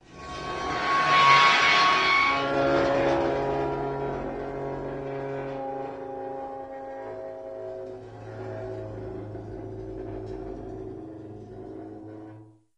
Metal Chalkboard Squeal Fast to Slow